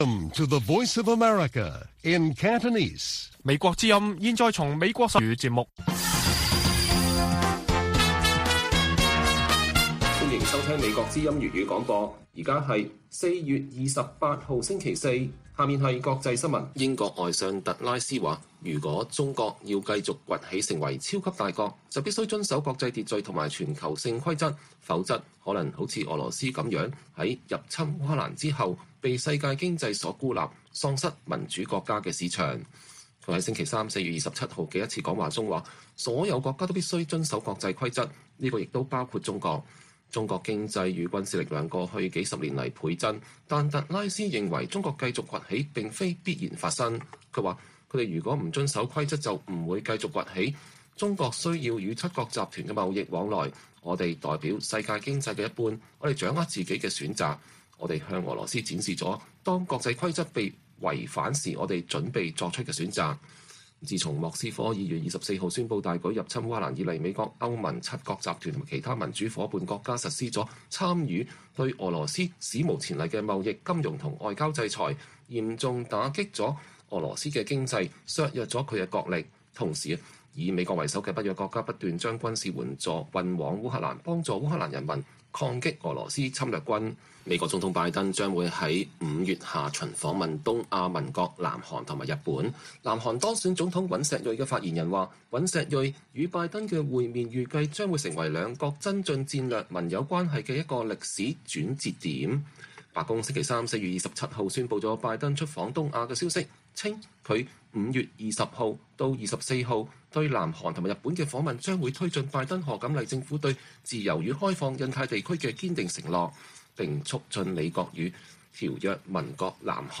粵語新聞 晚上9-10點: 英國外相敦促北約幫助台灣自衛，指中國必須遵守國際規則